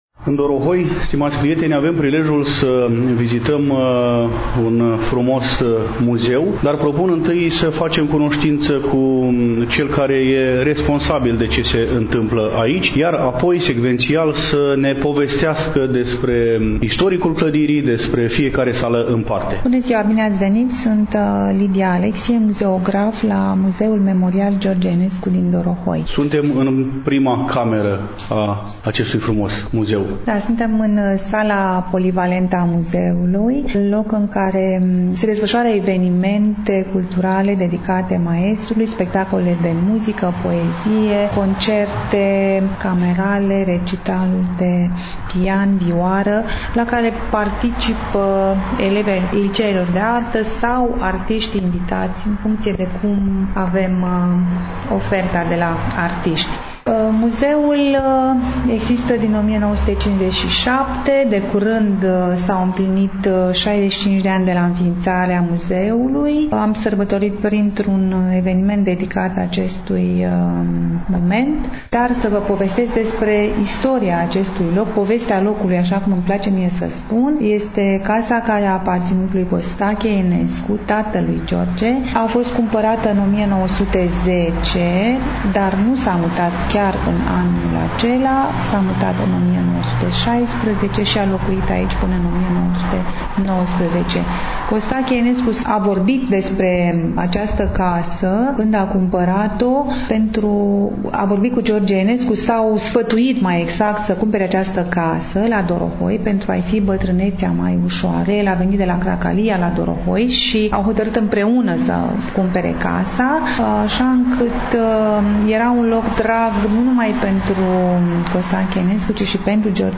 În ediția de astăzi a emisiunii noastre, poposim în incinta Muzeului Memorial „George Enescu” din Dorohoi